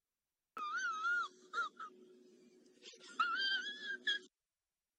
Play Risadinha Engraçada - SoundBoardGuy
Play, download and share Risadinha engraçada original sound button!!!!